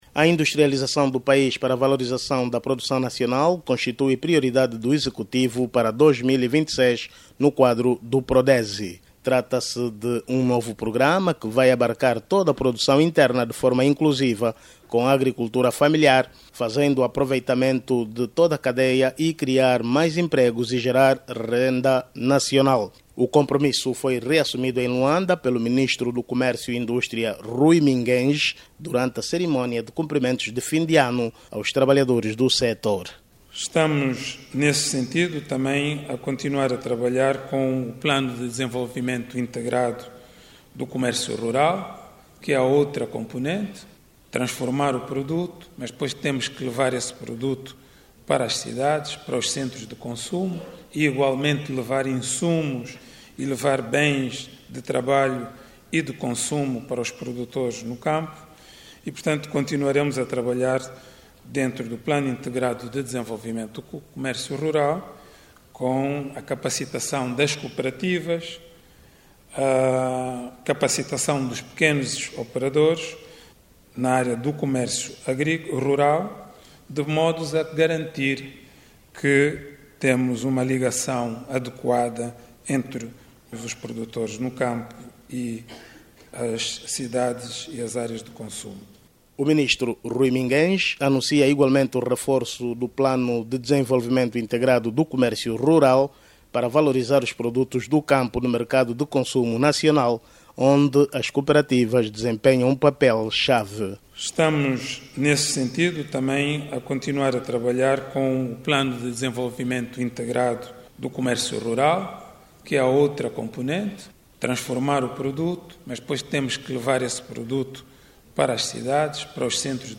O Ministro Rui Mingues fala ainda na valorização da população agrícola, na criação de novos empregos e na geração de renda.